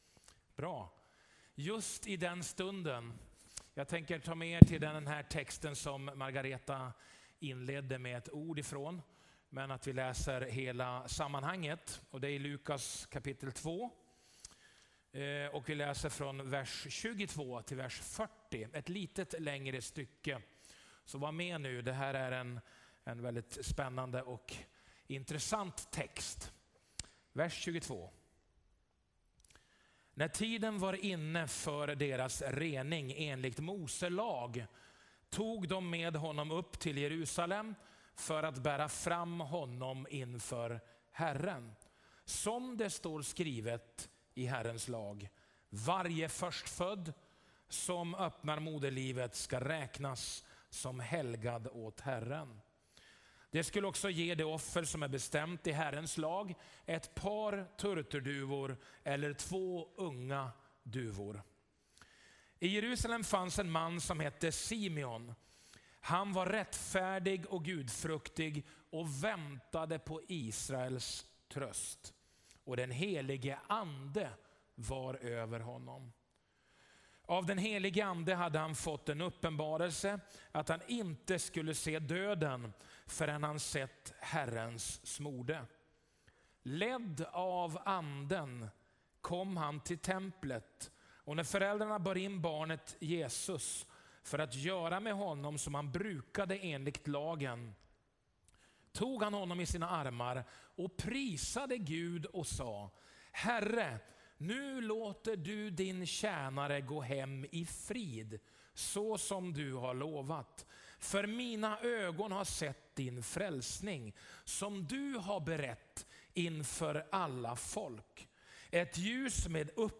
Gudstjänst 2 februari 2020